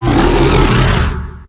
Cri d'Angoliath dans Pokémon HOME.